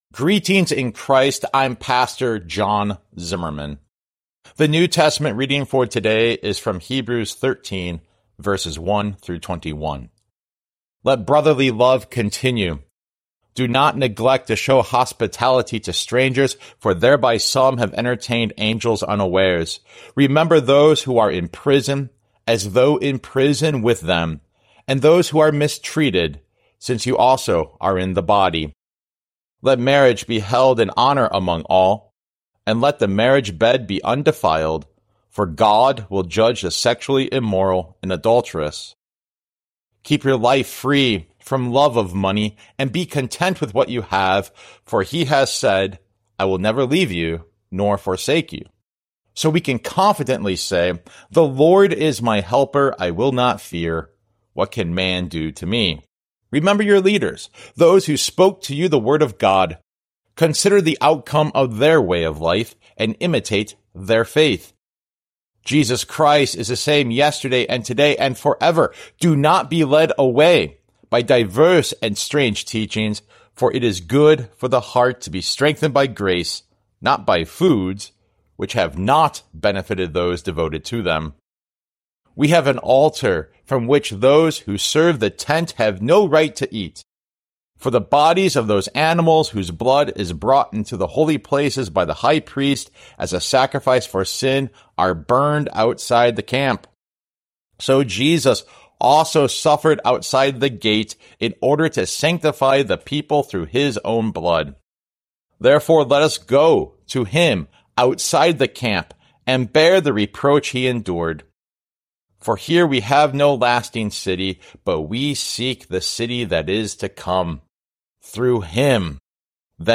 Morning Prayer Sermonette: Hebrews 13:1-21